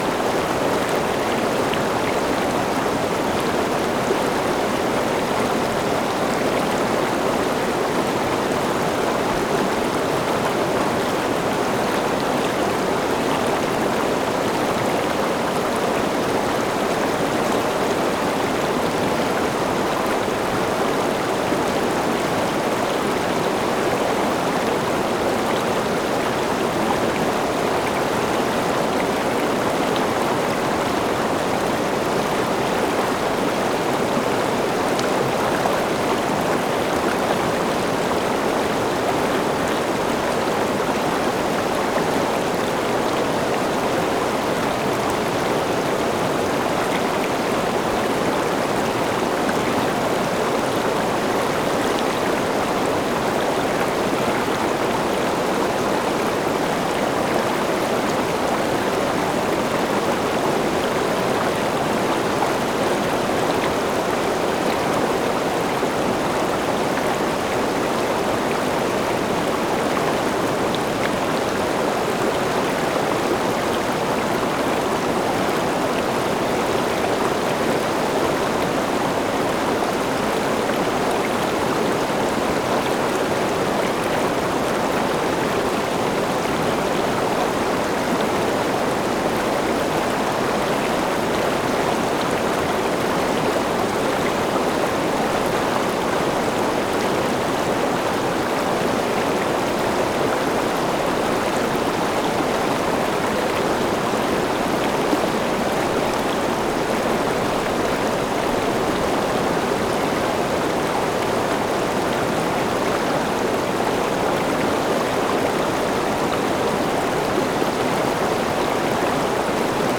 River-Waterfall.ogg